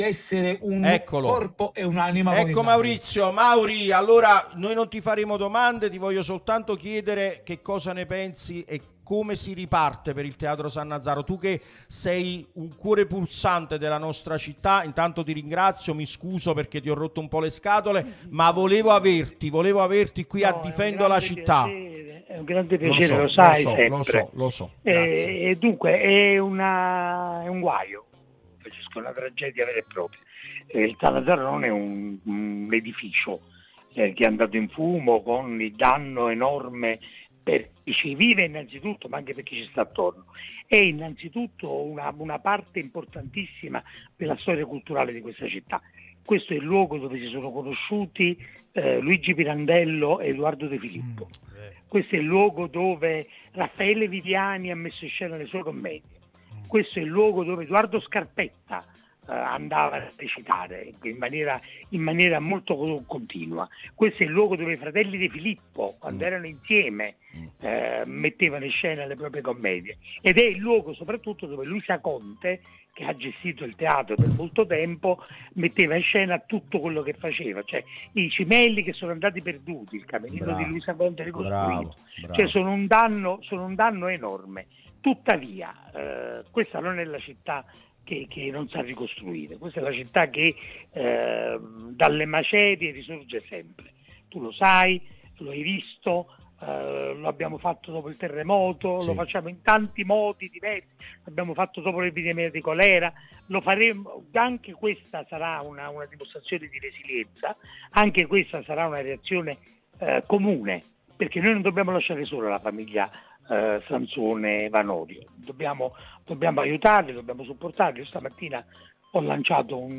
Maurizio De Giovanni, celebre scrittore, è intervenuto nel corso di 'Difendo la Cittài', trasmissione sulla nostra Radio Tutto Napoli, prima radio tematica sul Napoli, in onda tutto il giorno, che puoi ascoltare/vedere qui sul sito o sulle app (qui per Iphone/Ipad o qui per Android).